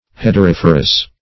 Search Result for " hederiferous" : The Collaborative International Dictionary of English v.0.48: Hederiferous \Hed`er*if"er*ous\, a. [L. hedera ivy + -ferous.] Producing ivy; ivy-bearing.